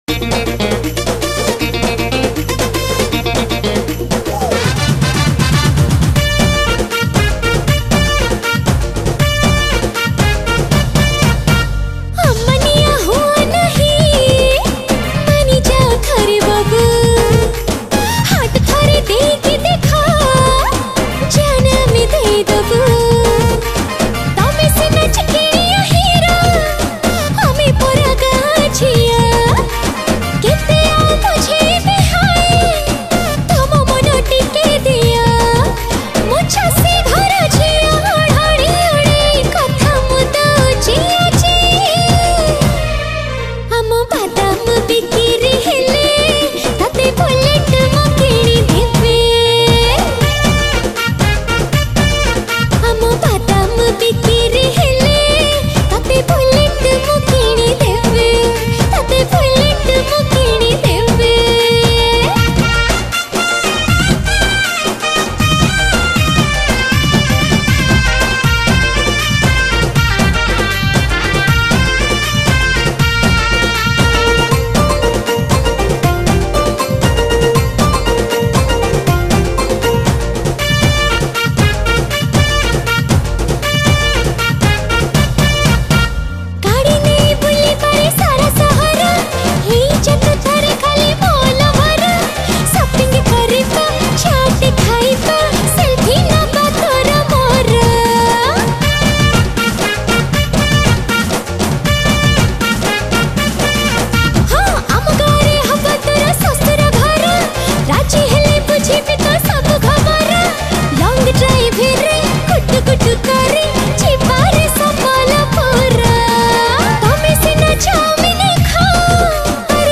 Keyboard
Drums